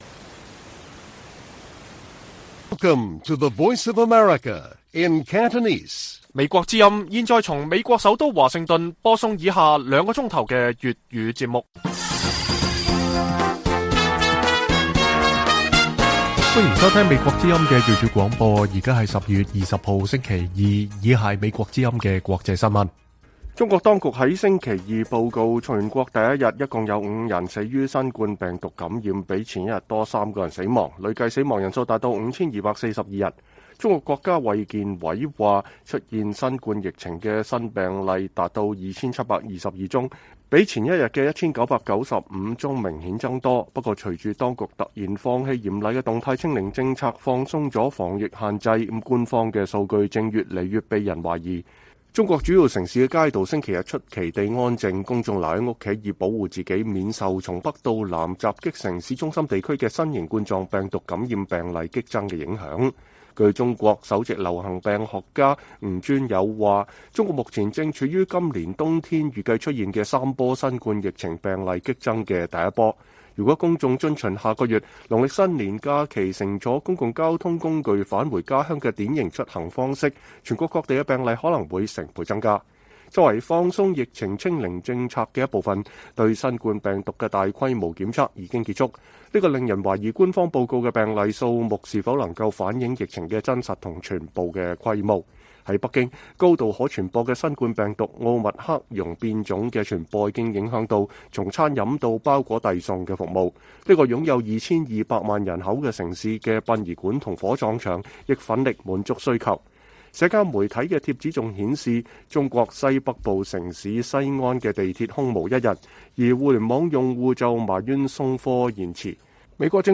粵語新聞 晚上9-10點: 中國新冠疫情繼續擴散 假日臨近可能出現三波疫情高峰